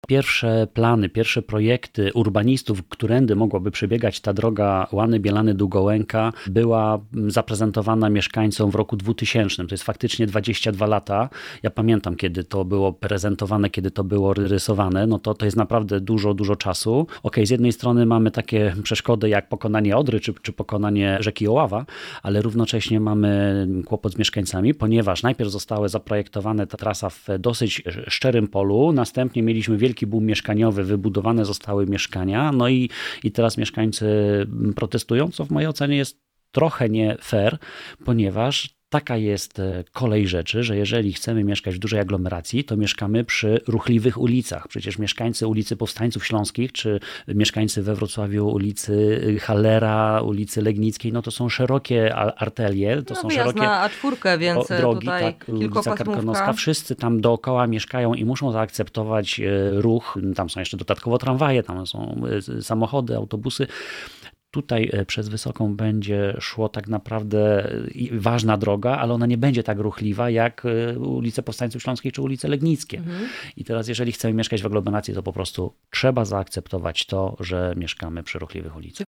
O inwestycji rozmawiamy z Michałem Bobowcem – radnym Sejmiku Województwa Dolnośląskiego.